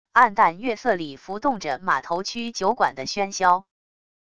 黯淡月色里浮动着码头区酒馆的喧嚣wav音频